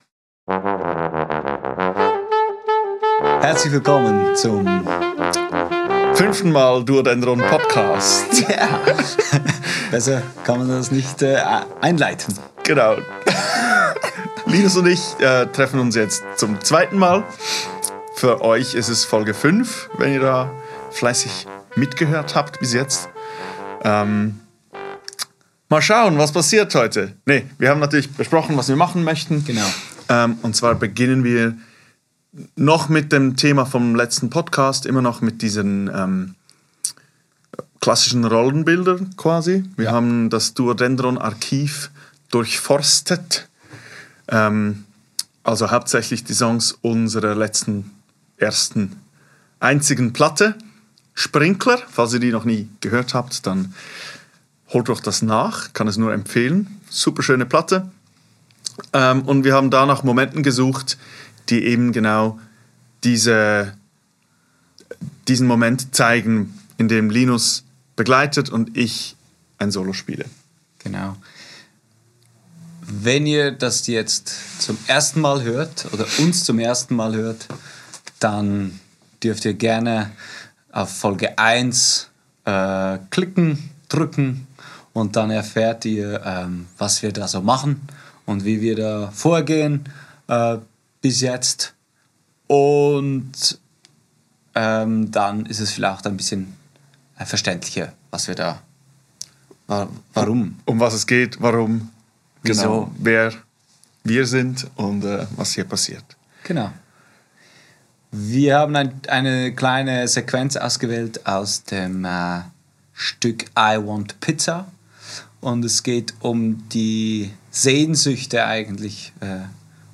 Aufgenommen am 17.04.2024 im Atelier